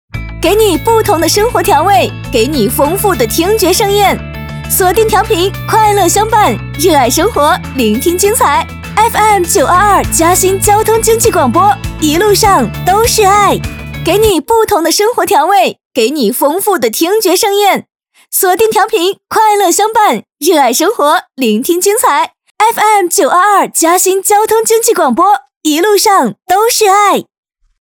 女390成熟知性配音-深度配音网
女390-台宣--欢快-.mp3